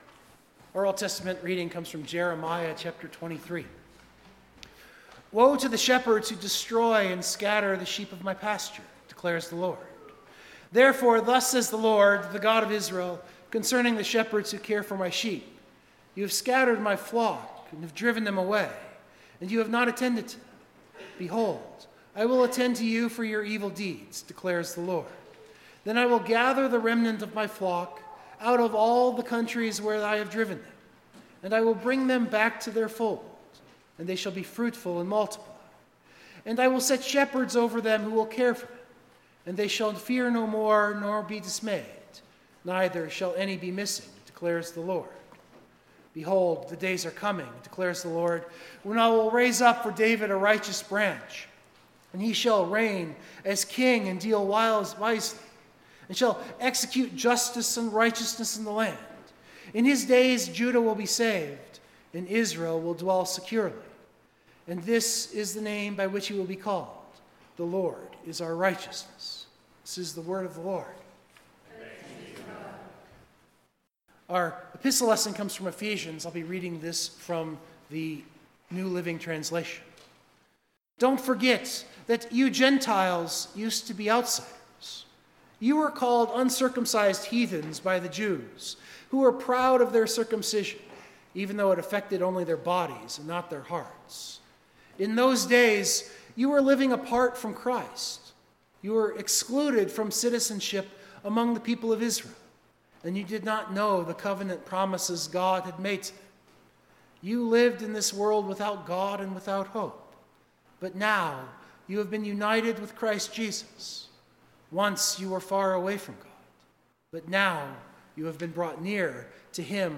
Biblical Text: Ephesians 2:11-22 Full Sermon Draft